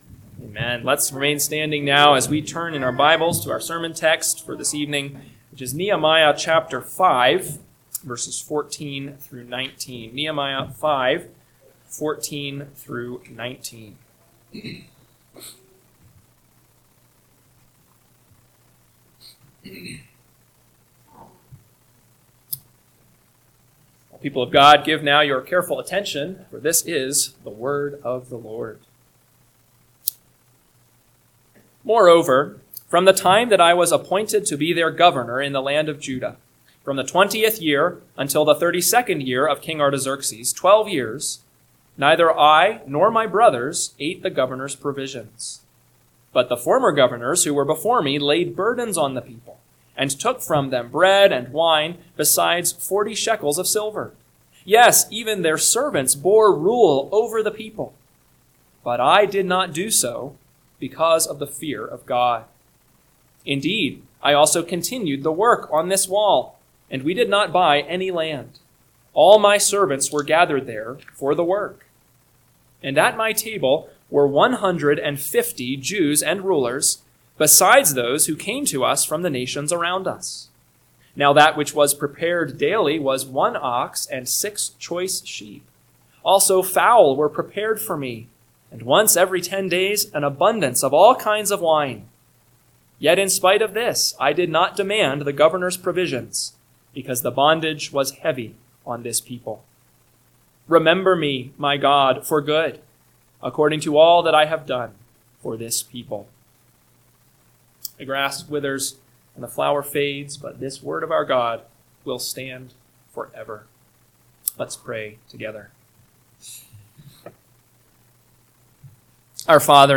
PM Sermon – 8/31/2025 – Nehemiah 5:14-19 – Northwoods Sermons